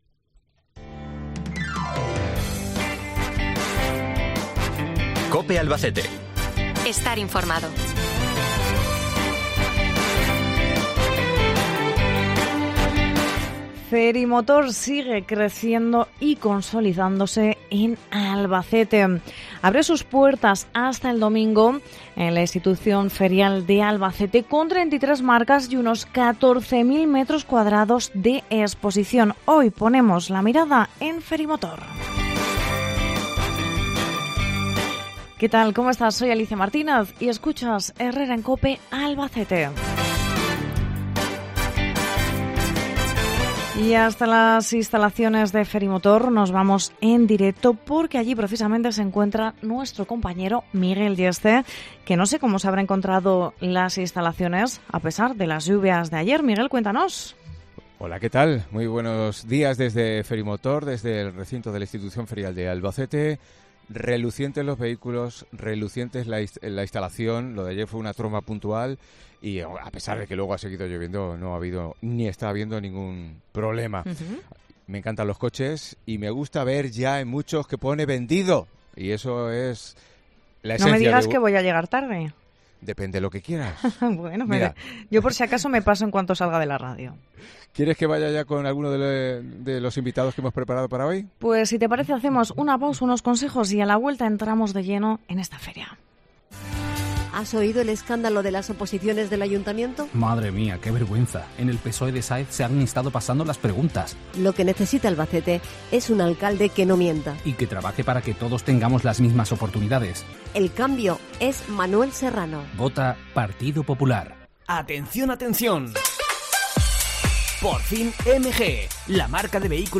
hoy con un programa especial desde FERIMOTOR